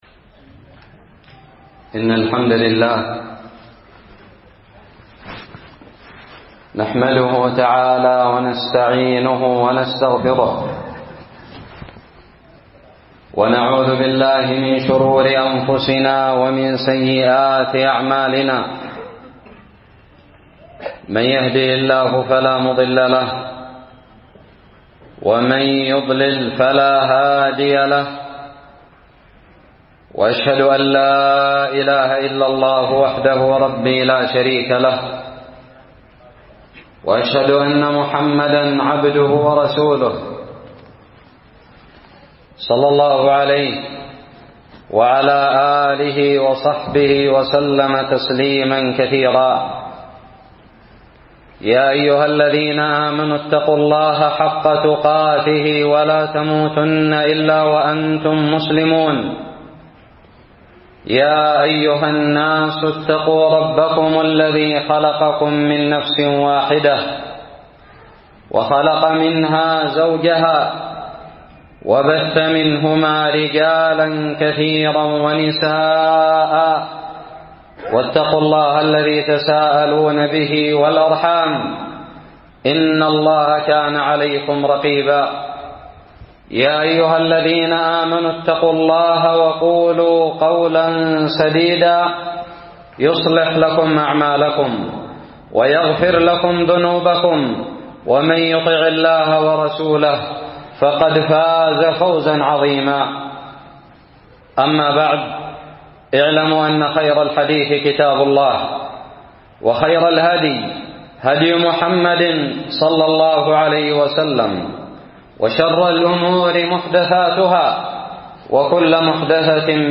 خطب الجمعة
ألقيت بدار الحديث السلفية للعلوم الشرعية بالضالع في 30 شعبان 1438هــ